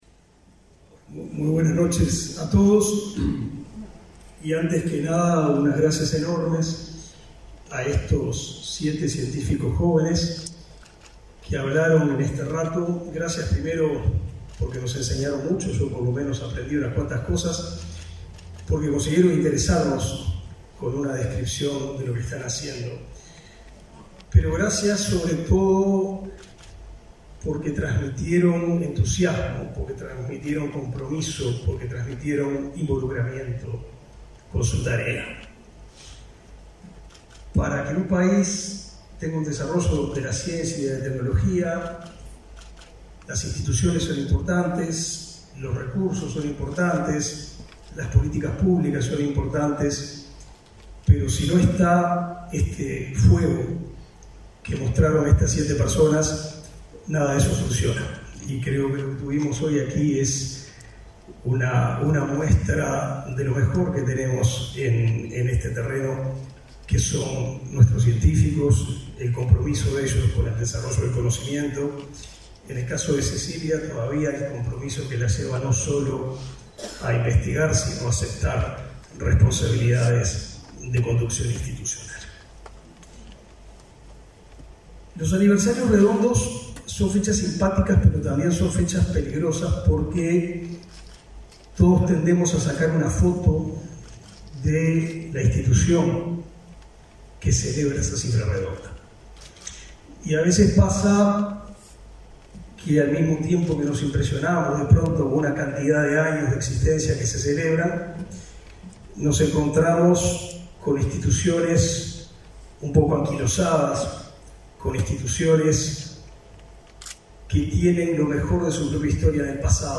Palabras del ministro de Educación y Cultura, Pablo da Silveira
El evento culminó con expresiones del ministro de Educación y Cultura, Pablo da Silveira.